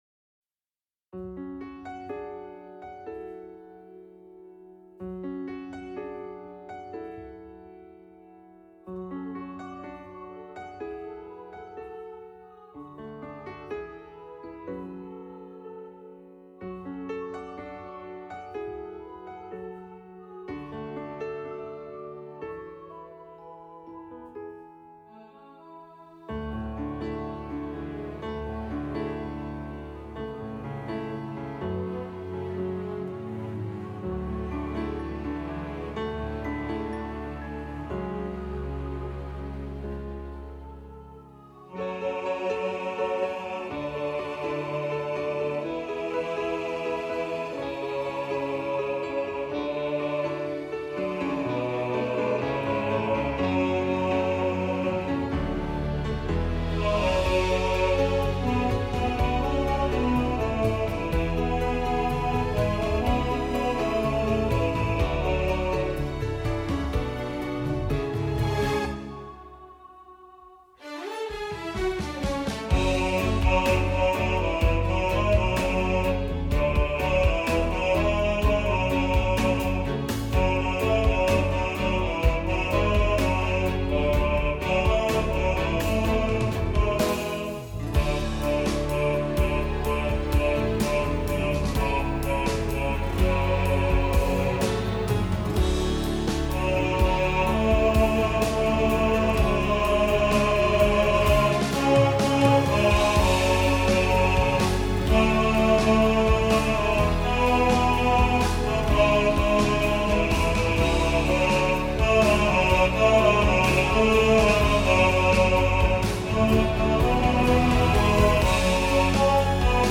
Speechless – Bass | Ipswich Hospital Community Choir
Speechless-Bass.mp3